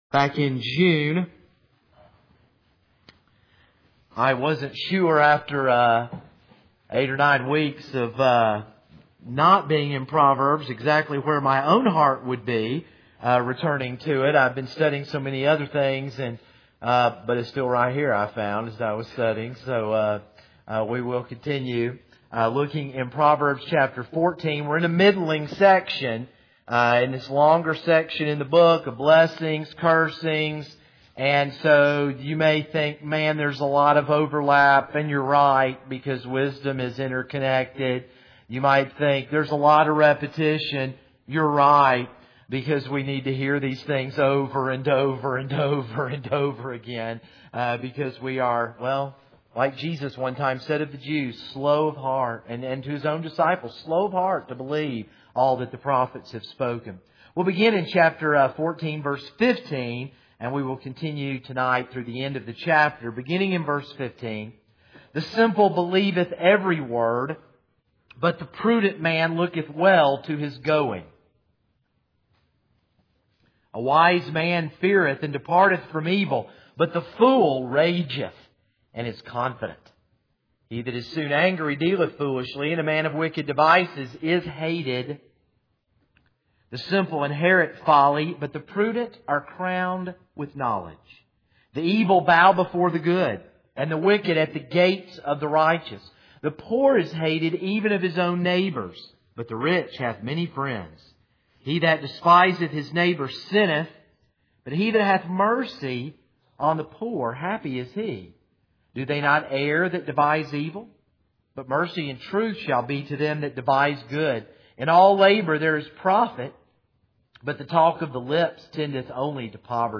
This is a sermon on Proverbs 14:16-35.